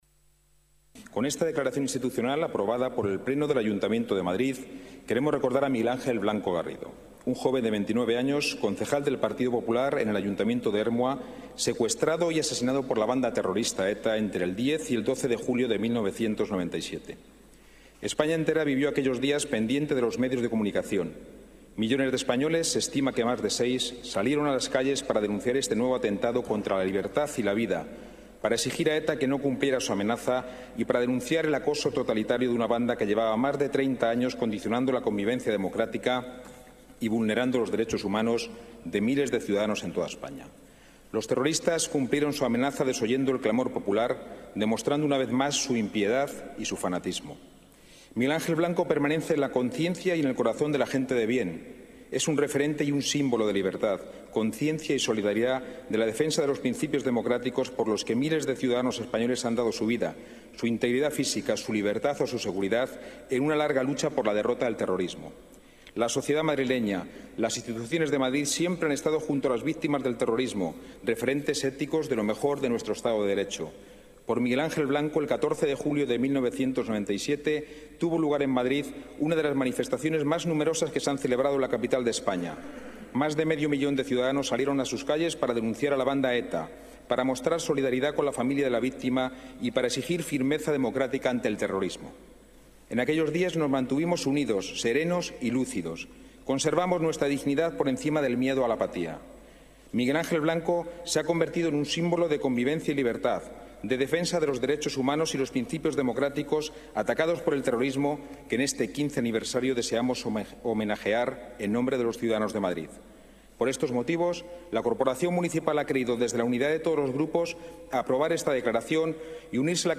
Nueva ventana:Declaraciones de Ángel Garrido, presidente del Pleno